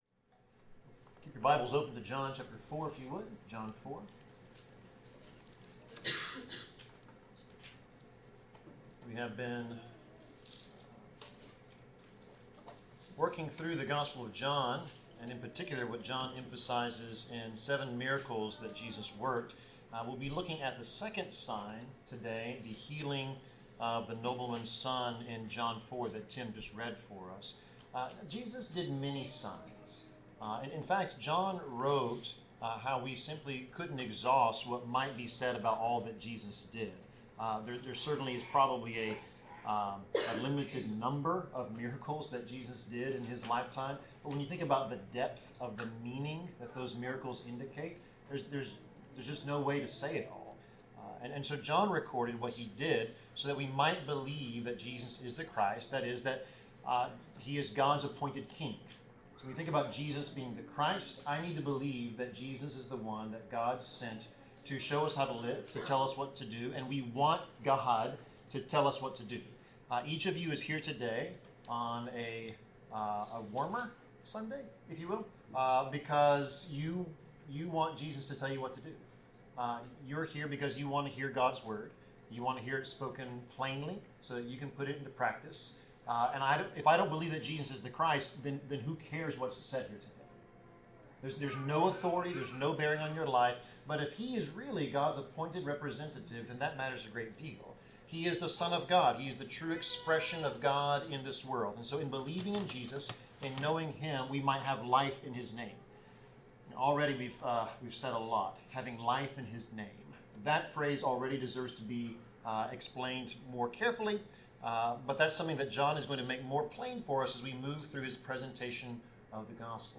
Passage: John 4:46-54 Service Type: Sermon